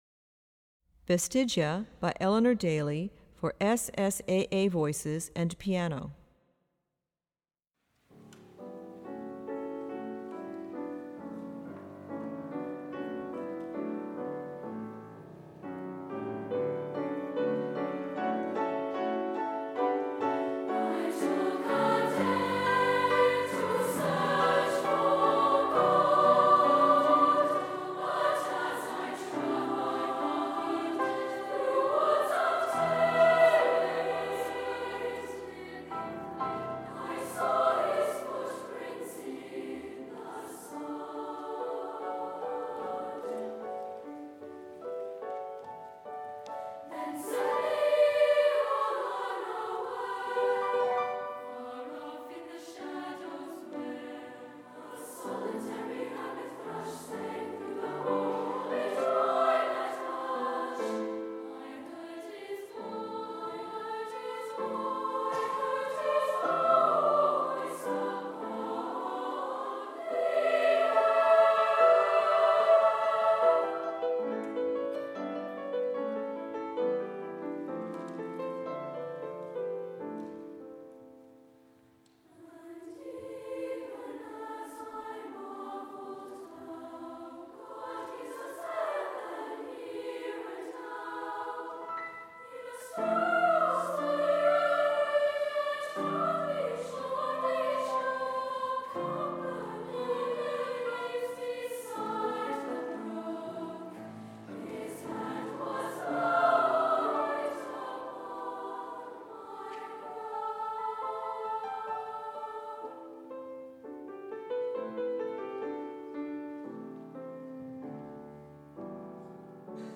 Voicing: SSAA